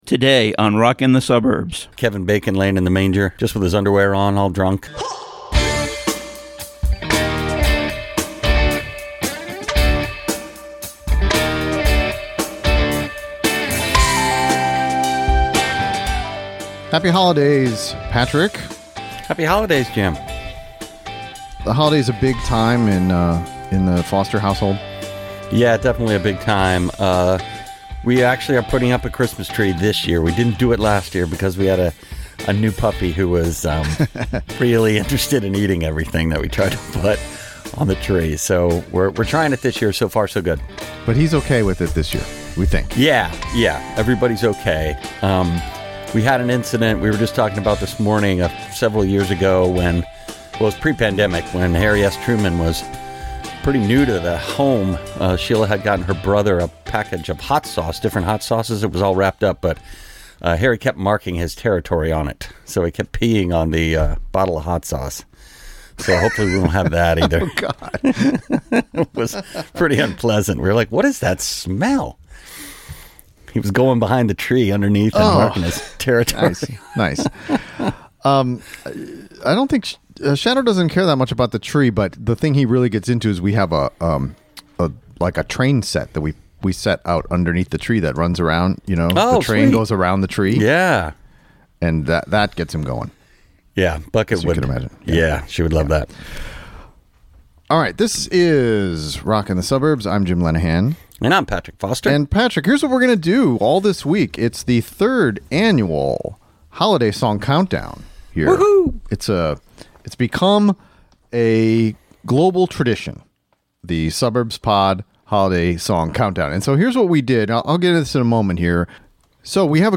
A live audience joins us on Zoom to add their insights.